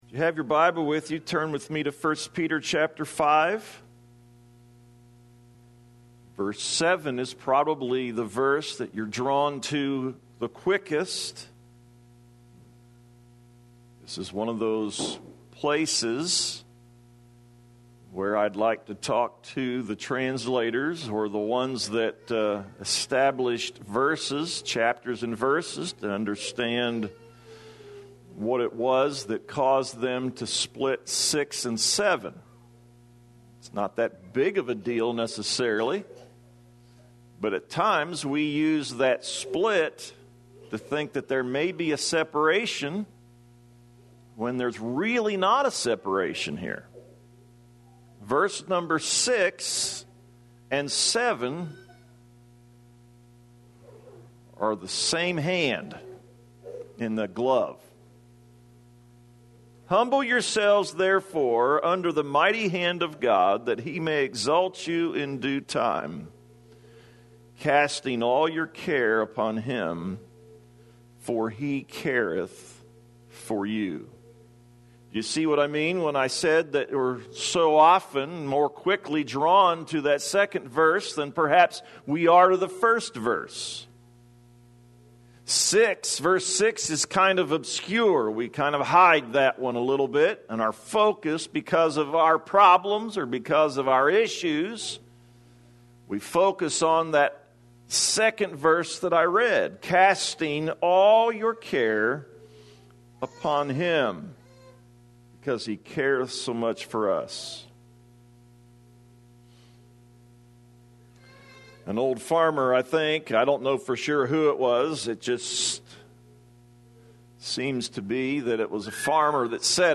2020-11-29-am-sermon.mp3